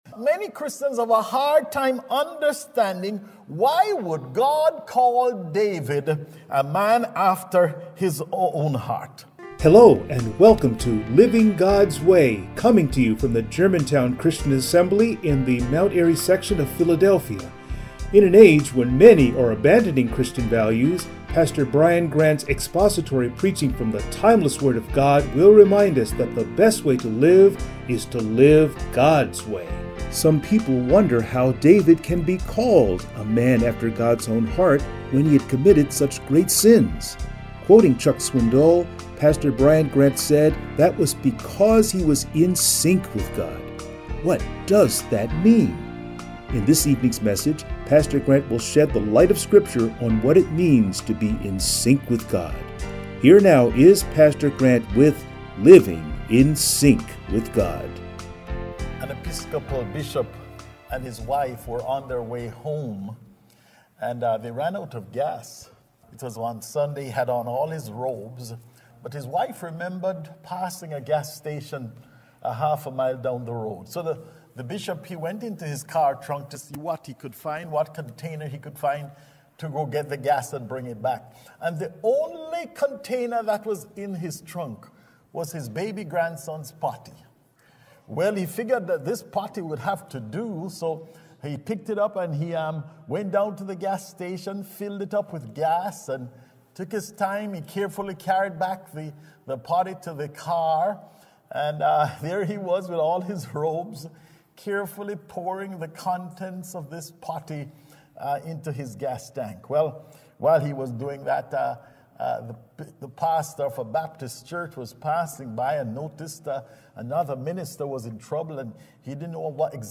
Passage: Acts 13:13-39 Service Type: Sunday Morning